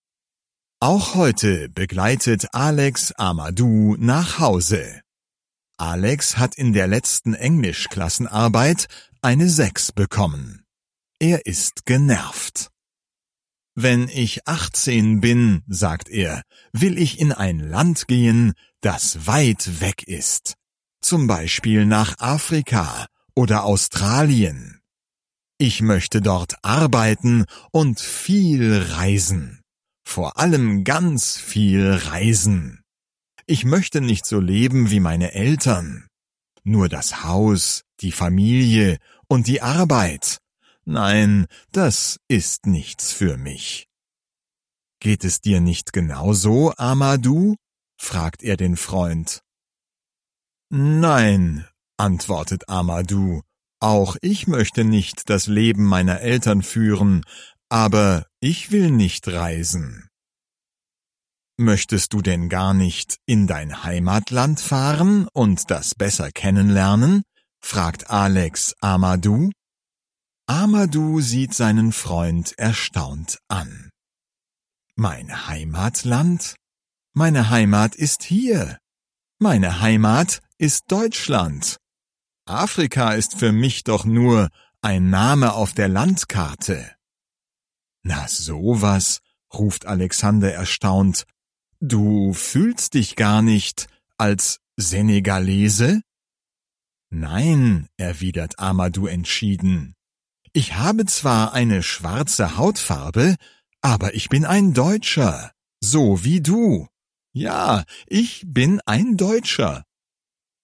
So nah, So fern (DE) audiokniha
Ukázka z knihy